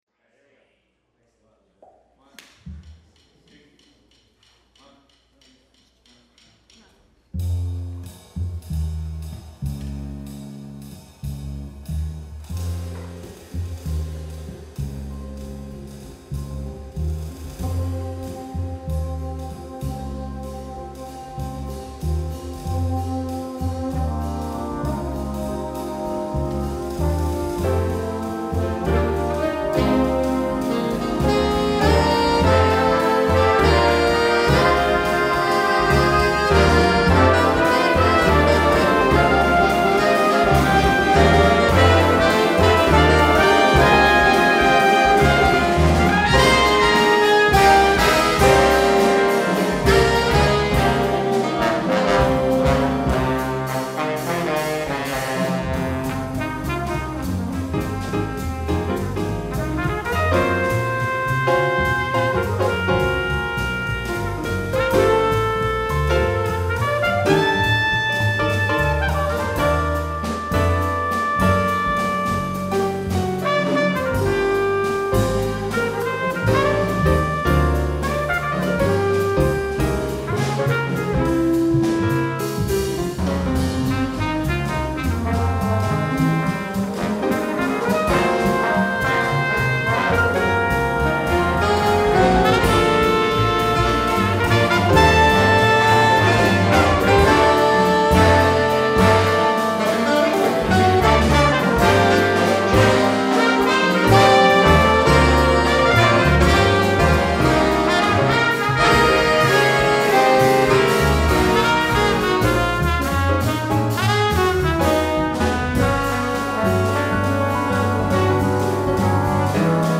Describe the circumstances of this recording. plays live